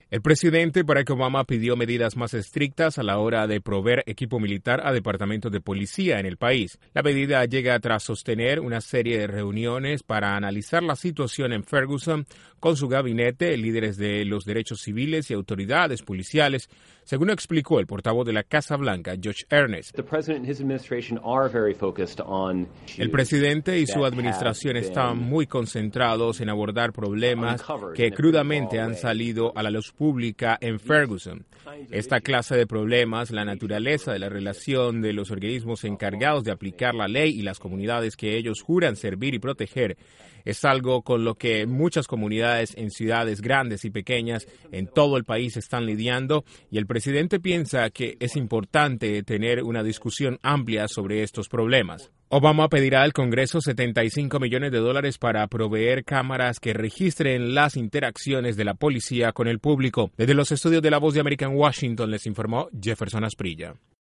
El presidente Obama dedicó una jornada completa para analizar la situación de Ferguson y pidió medidas más estrictas a la hora de proveer equipo militar a la policía. Desde la Voz de América en Washington informa